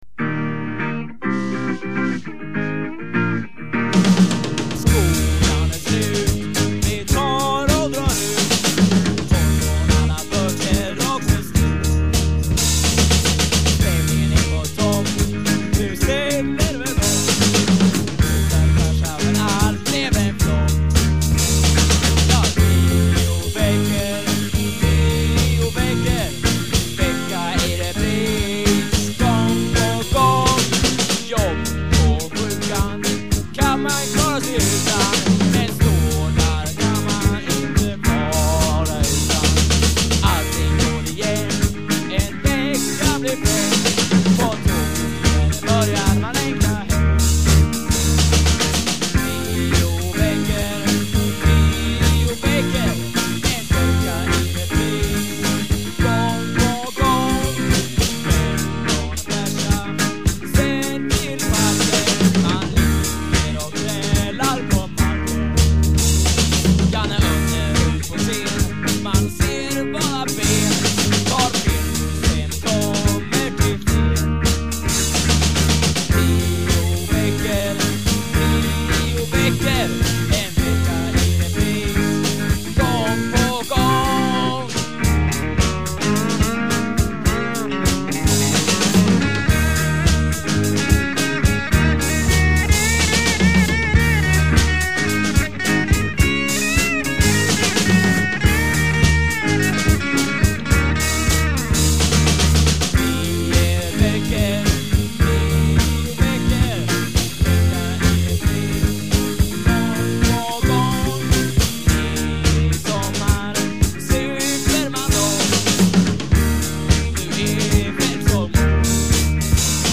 Guitar
Voice
Bass
Drums
Keyboards
Recorded in Blästadgården and at some basements in Vidingsjö